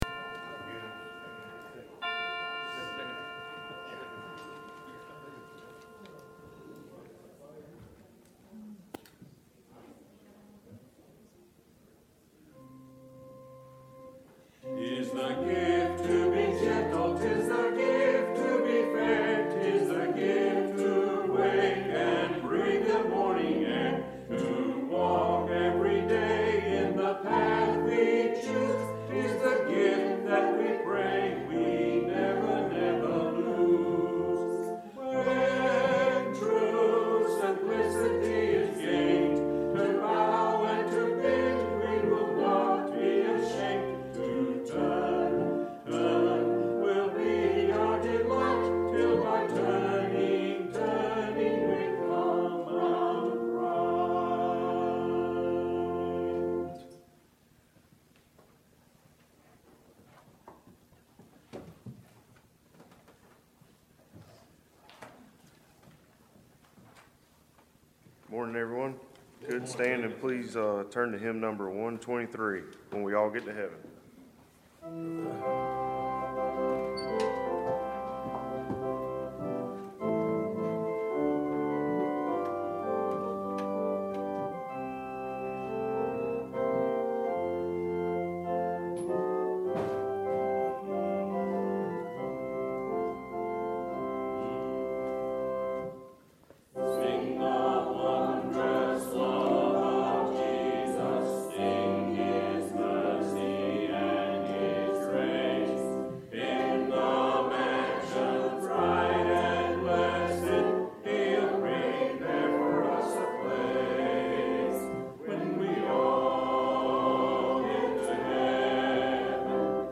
The Audio is in two parts due to a recording difficulty on the Sound System.
Psalm 91 Service Type: Sunday Worship The Audio is in two parts due to a recording difficulty on the Sound System.